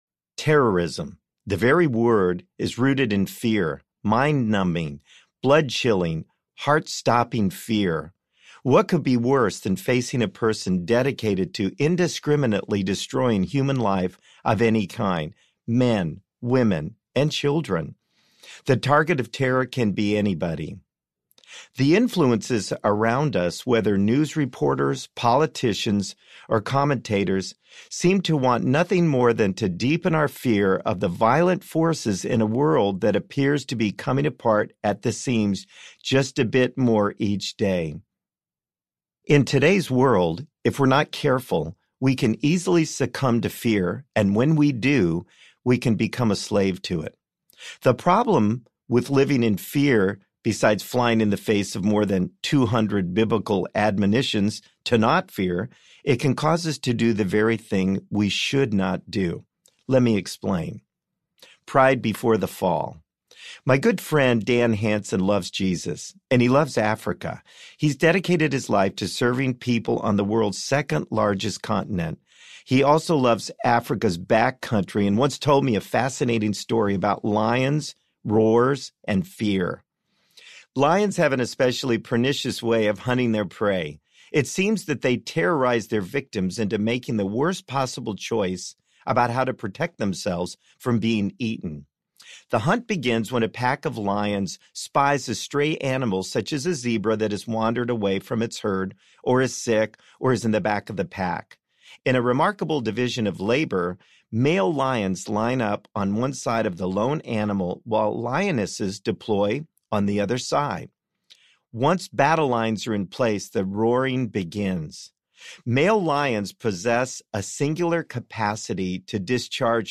Standing in the Fire Audiobook
Narrator